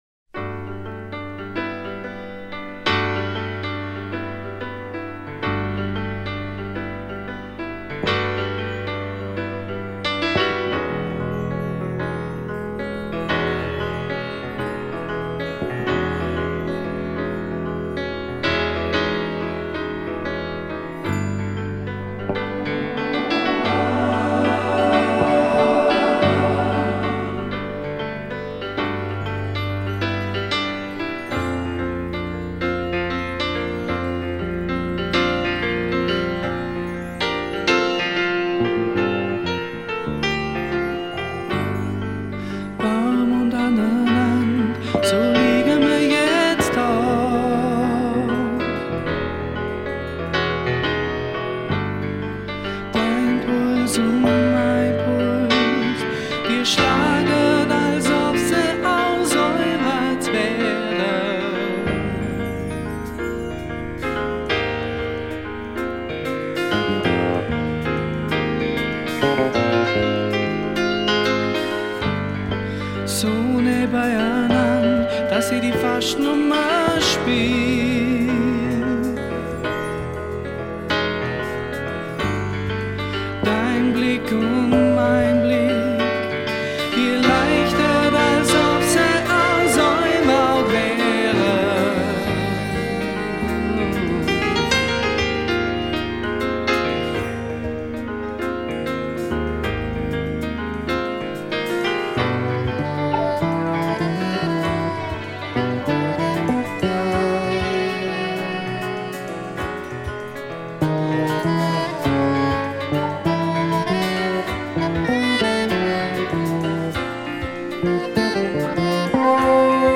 Keyboards, Vocals
Saxophon
Guitar
Electric Bass
Drums, Percussion